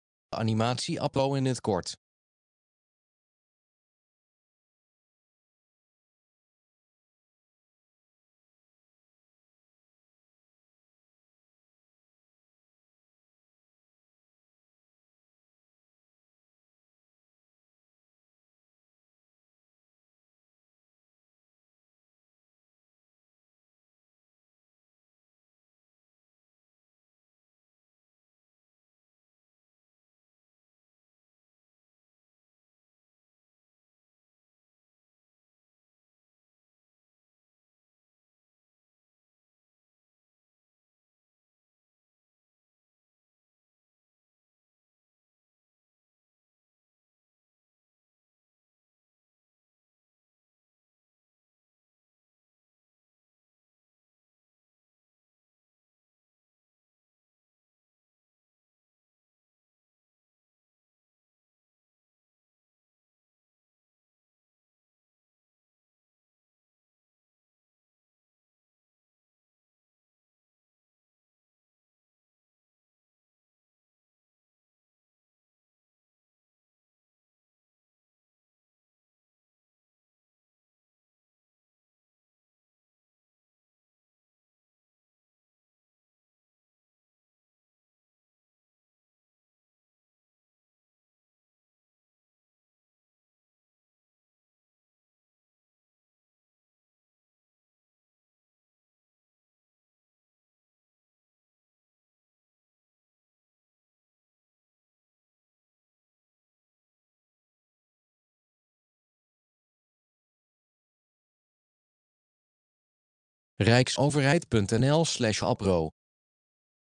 Deze animatievideo van de Rijksoverheid geeft uitleg over de Algemene Beveiligingseisen voor Rijksoverheidsopdrachten, de ABRO. De animatie visualiseert de tekst van de voice-over.